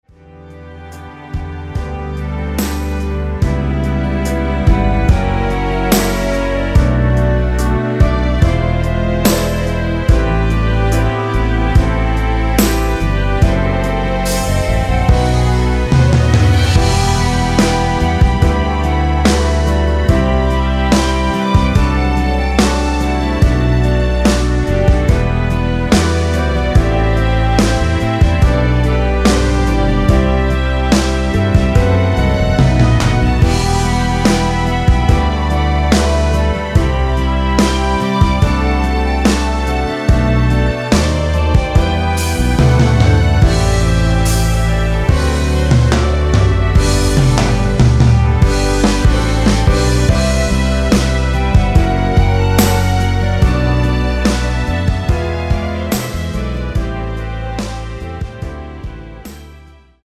원키 멜로디 포함된 MR입니다.
앞부분30초, 뒷부분30초씩 편집해서 올려 드리고 있습니다.
중간에 음이 끈어지고 다시 나오는 이유는
(멜로디 MR)은 가이드 멜로디가 포함된 MR 입니다.